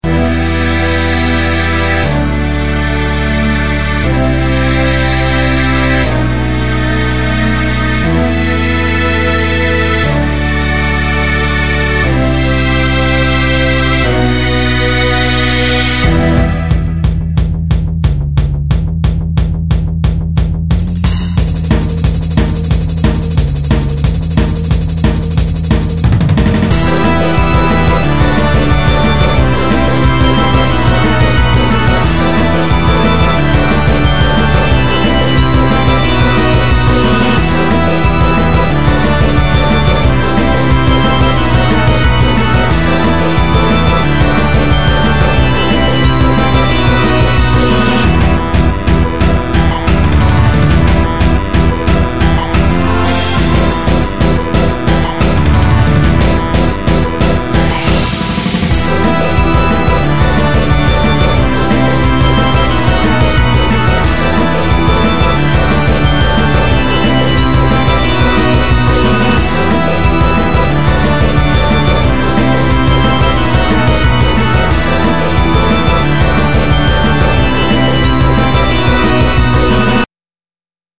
BGM
スローテンポファンタジー